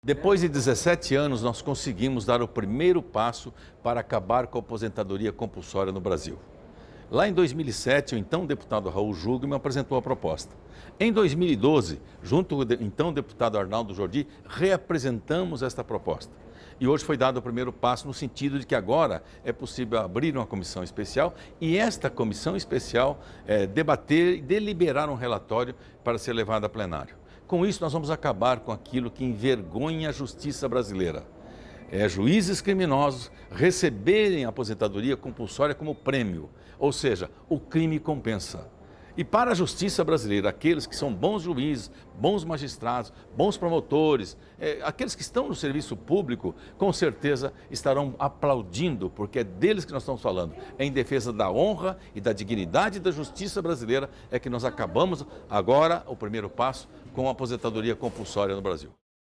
Ouça o deputado Rubens Bueno comentando os primeiros passos para acabar com uma vergonha da Justiça Brasileira: